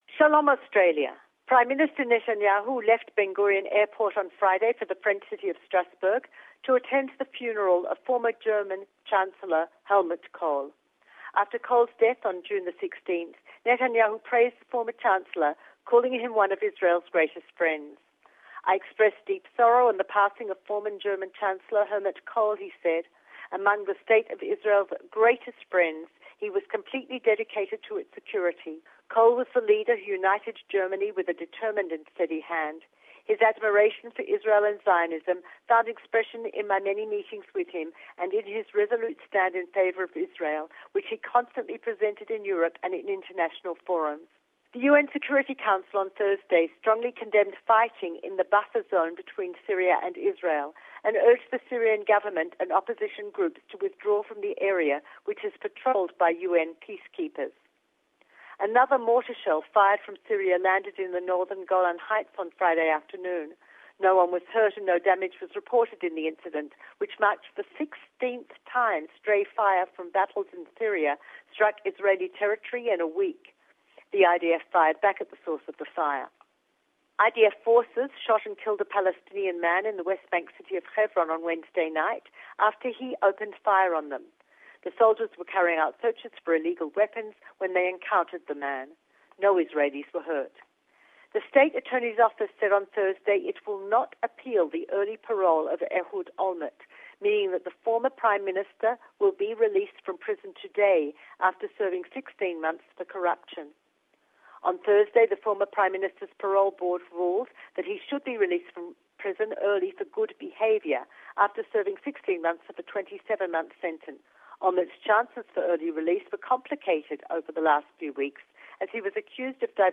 Don't miss our weekly report from Jerusalem